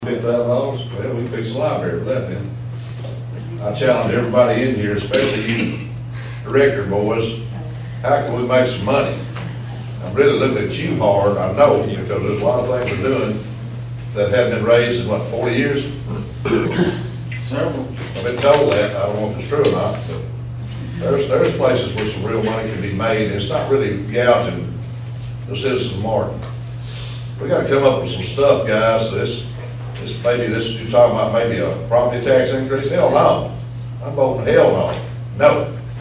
At Martin’s Finance Committee meeting last week, the committee discussed a plan to help lower the City’s debt by charging UTM students….
Though the idea could raise the city an extra 50 thousand dollars, City Board Alderman Terry Hankins says, there has to be another way.